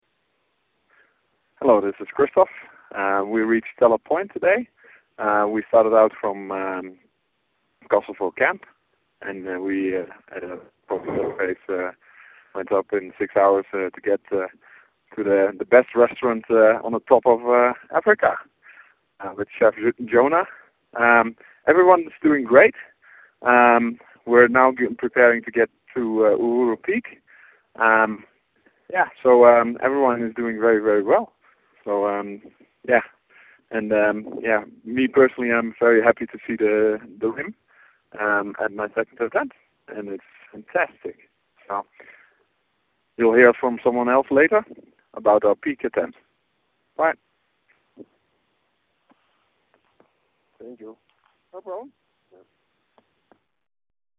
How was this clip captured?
September 10, 2012 – A Call from the Best Restaurant on the Top of Africa!